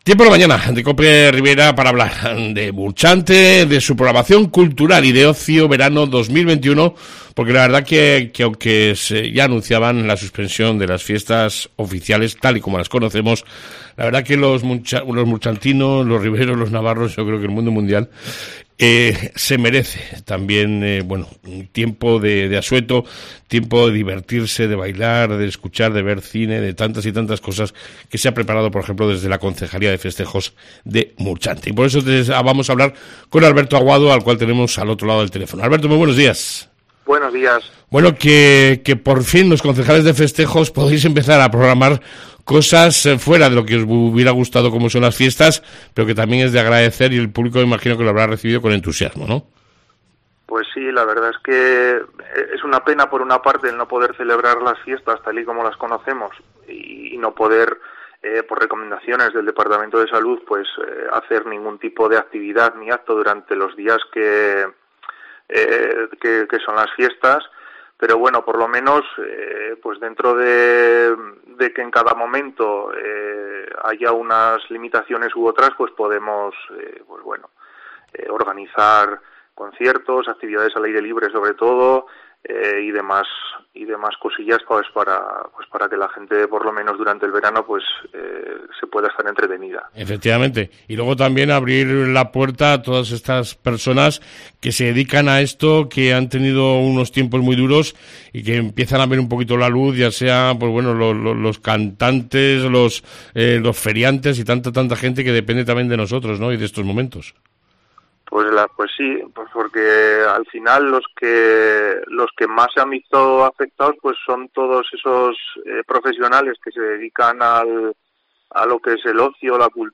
AUDIO: Entrevistamos al Concejal de festejos Alberto Aguado